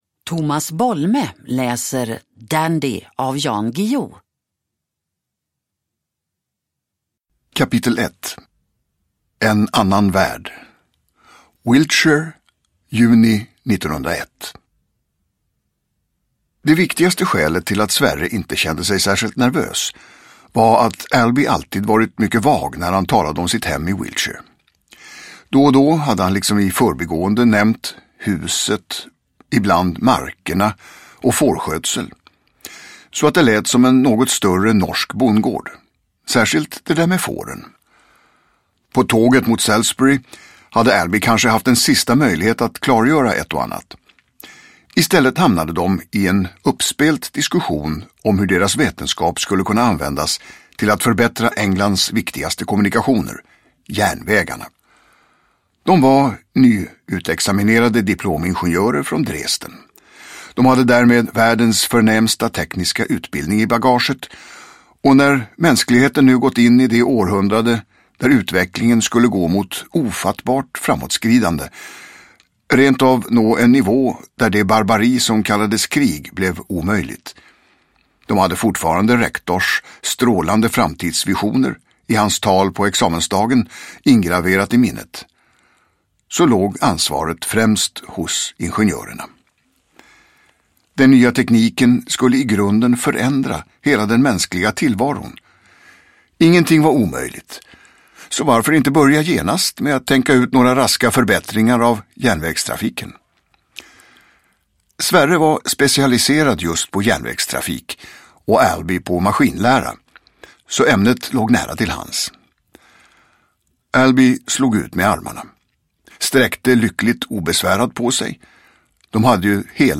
Dandy / Ljudbok